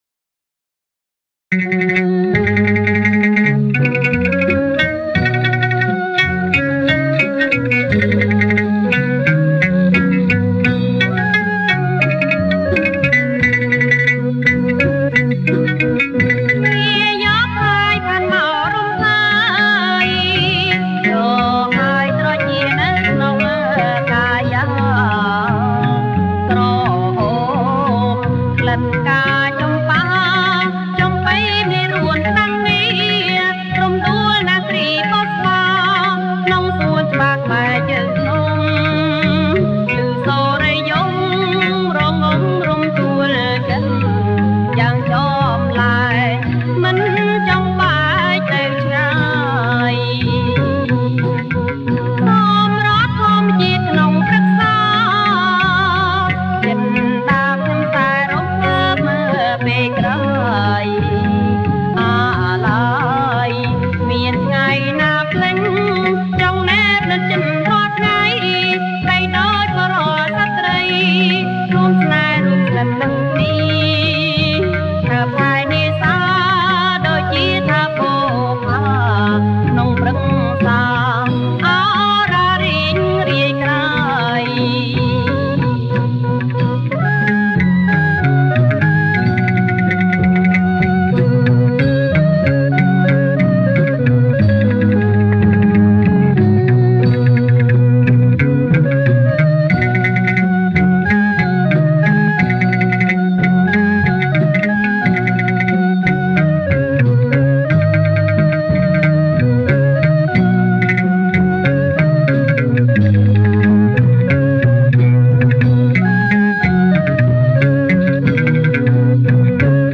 • ប្រគុំជាចង្វាក់ រាំក្បាច់